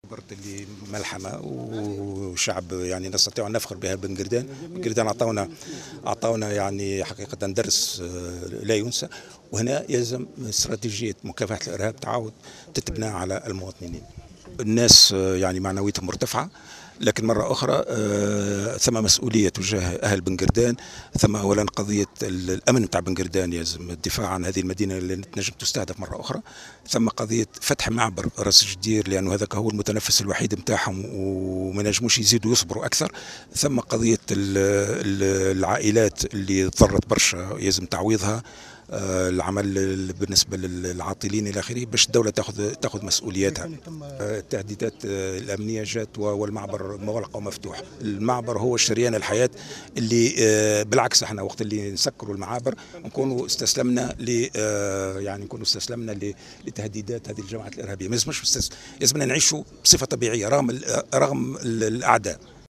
دعا الرئيس السابق منصف المرزوقي لإعادة فتح معبر راس جدير الذي يعتبر شريان الحياة لمنطقة بنقردان، بحسب تعبيره في تصريحات صحفية على اثر اشرافه اليوم على اجتماع لحزبه حراك تونس الإرادة في القيروان.